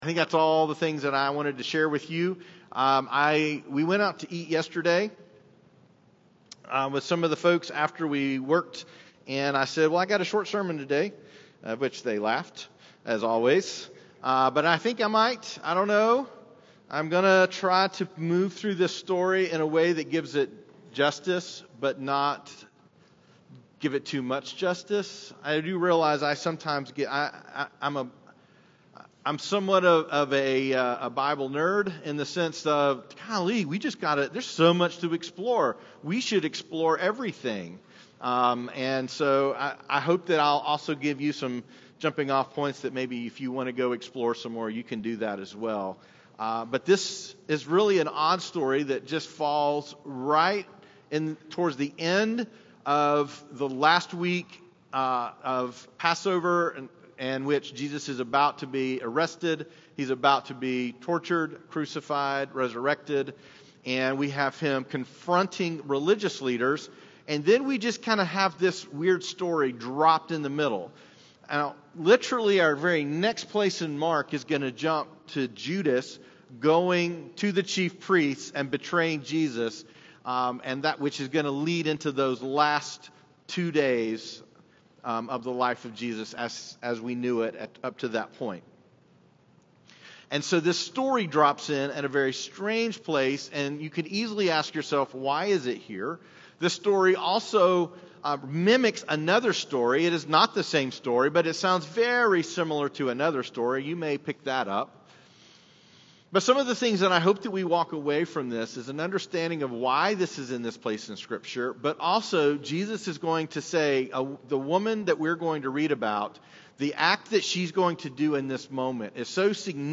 Latest Sermon - Journey Church